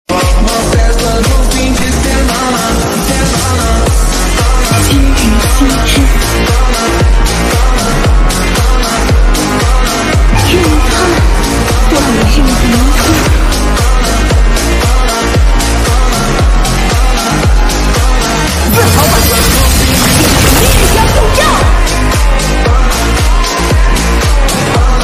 Mp3 Sound Effect Hiệu ứng thay đổi thế giới